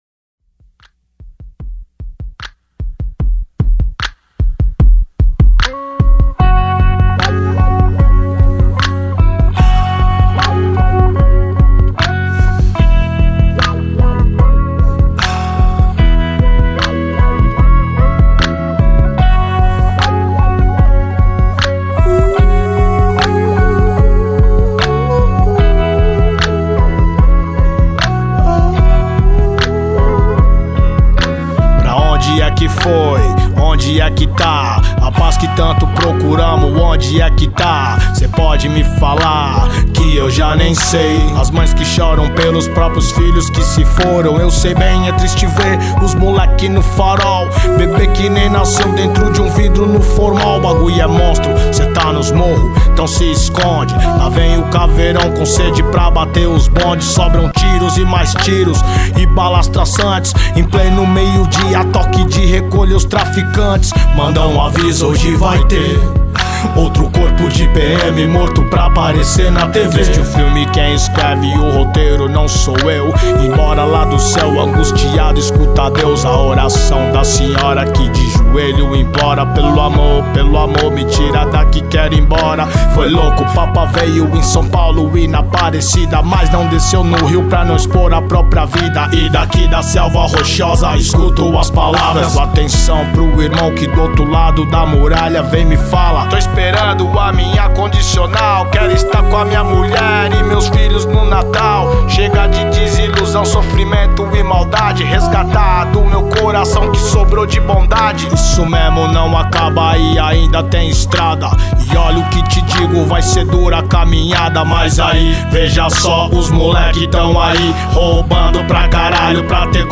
RAP.